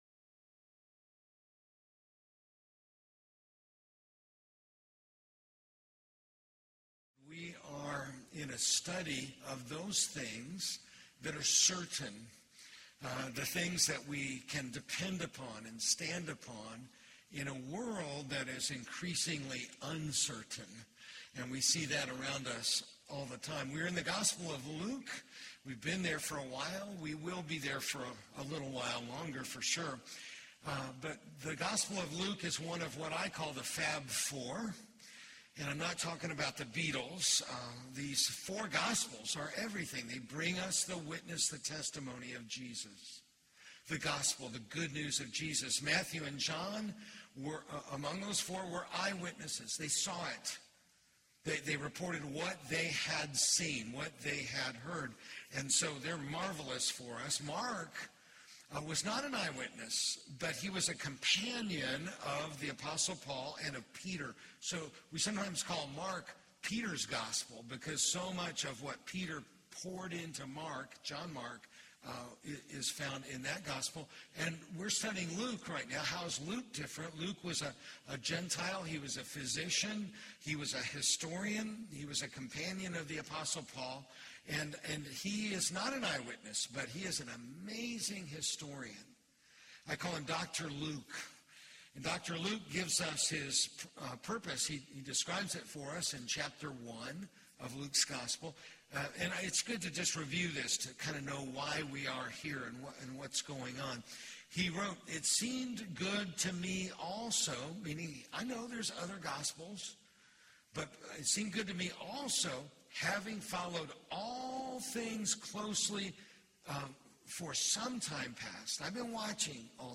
Faith Fellowship Church Sermons Podcast - A Certain Family | Free Listening on Podbean App